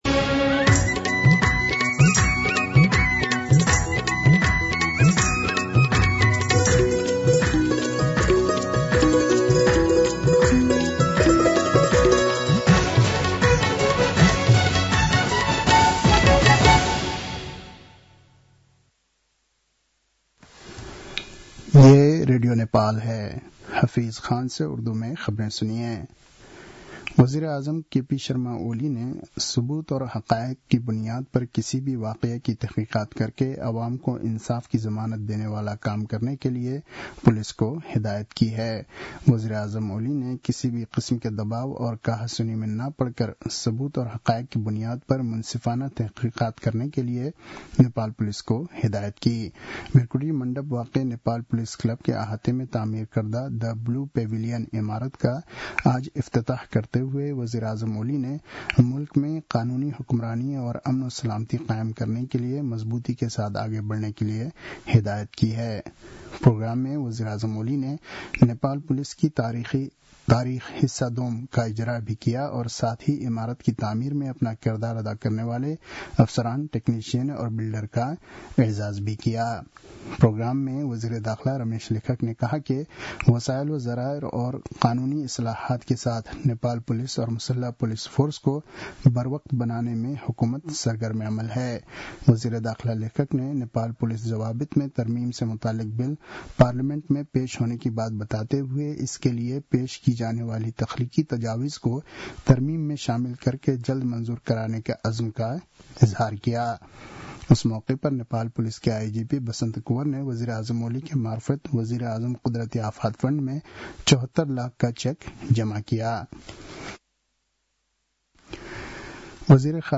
उर्दु भाषामा समाचार : २७ माघ , २०८१
Urdu-News-10-26.mp3